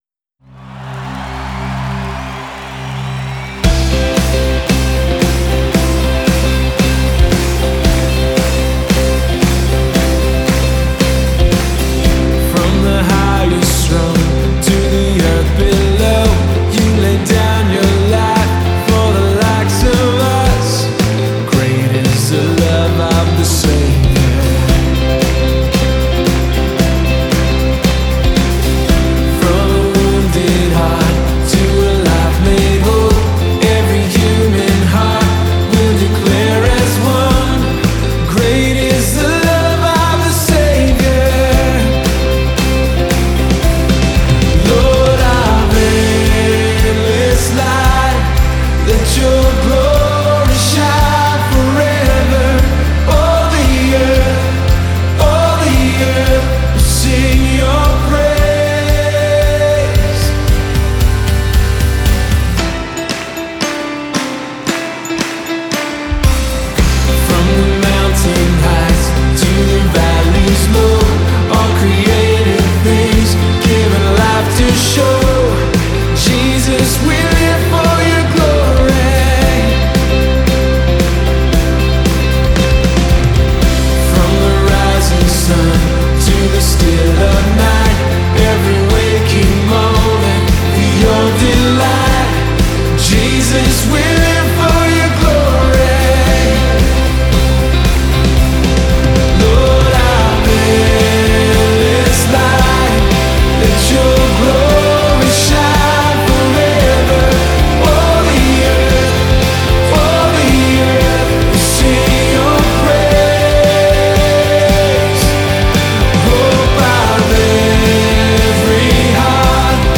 ANCHORSONG, med lovsångsband, panelsamtal och fika